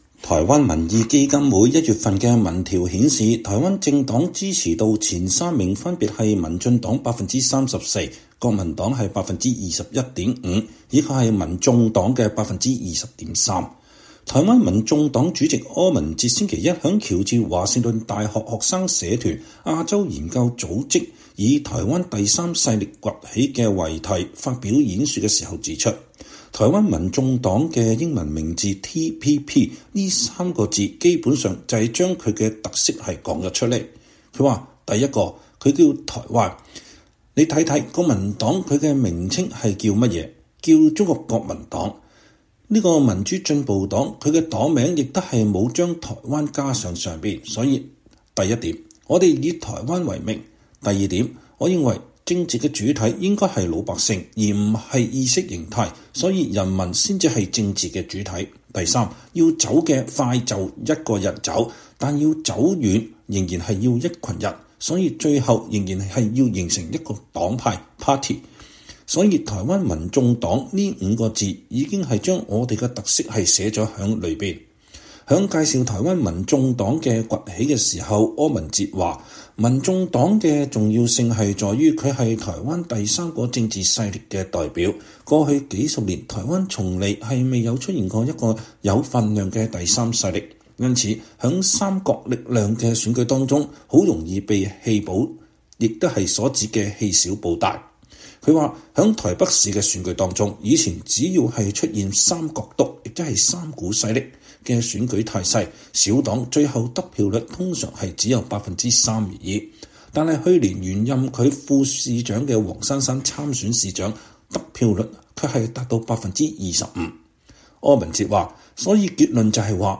在華盛頓對美大學生演說，台灣民眾黨主席柯文哲：維持現狀是不得已的選擇，兩岸應重啟對話
台灣民眾黨主席、前台北市長柯文哲星期一(4月17日)在華盛頓一所大學對學生髮表演說時針對兩岸關係表示，多數台灣人民不接受北京當局的“一國兩制”，九成以上的台灣人想要維持現狀，這不僅是唯一的選擇，恐怕也是“不得已的選擇”，因此他主張兩岸應該重啟原有的海基會、海協會對話平台，恢復交流溝通。